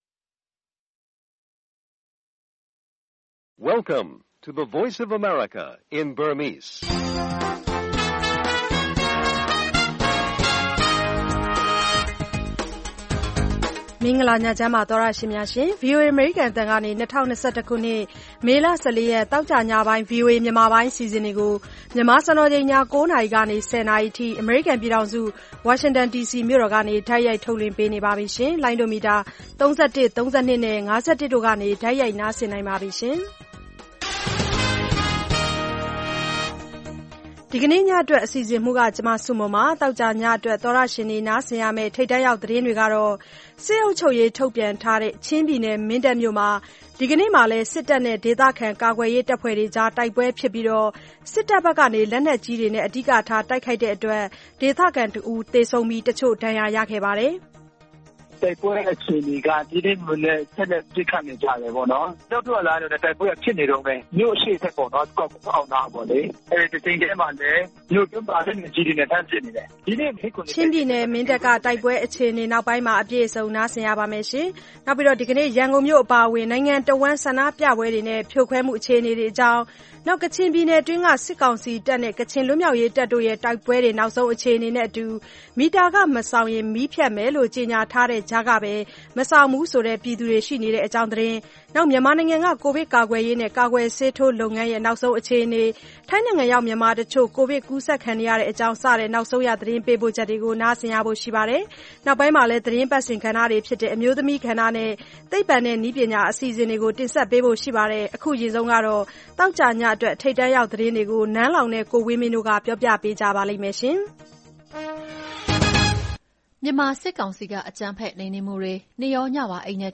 မြန်မာနဲ့ နိုင်ငံတကာ ထိပ်တန်းသတင်းနဲ့ သတင်းပေးပို့ချက်များ၊ အမျိုးသမီးကဏ္ဍနဲ့ သိပ္ပံနဲ့နည်းပညာ အပတ်စဉ်ကဏ္ဍများအပါအဝင် သောကြာည ၉း၀၀-၁၀း၀၀ နာရီ ရေဒီယိုအစီအစဉ်